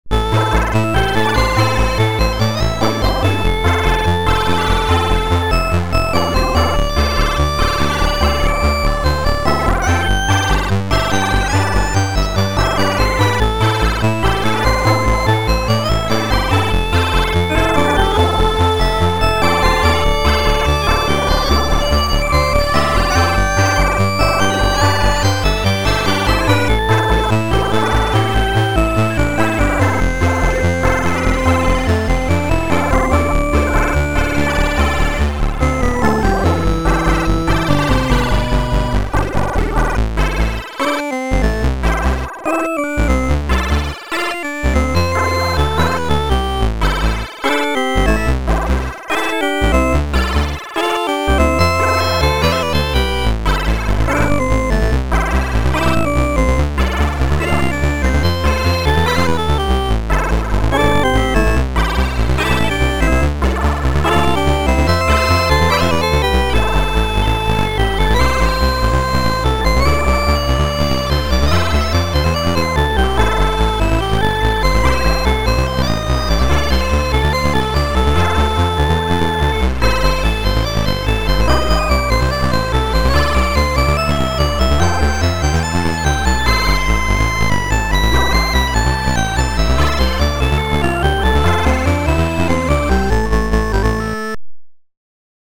Amiga
8-bit beep boop